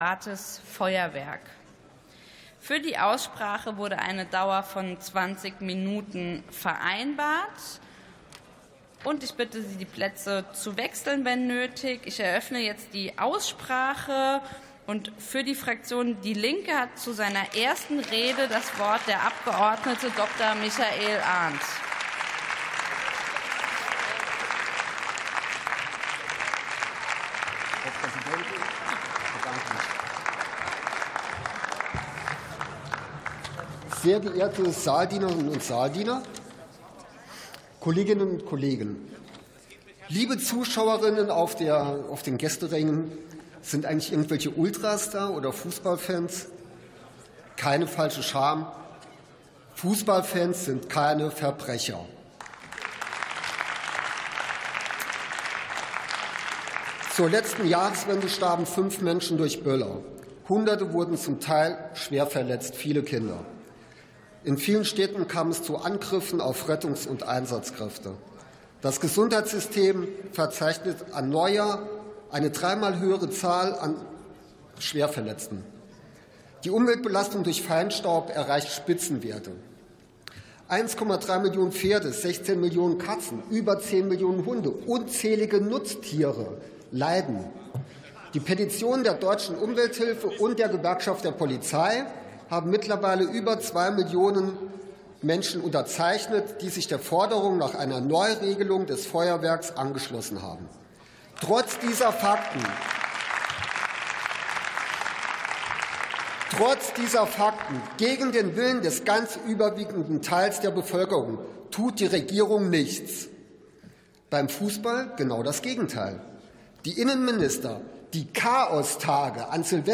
Sitzung vom 04.12.2025. TOP 22: Lokale Regeln für privates Feuerwerk ~ Plenarsitzungen - Audio Podcasts Podcast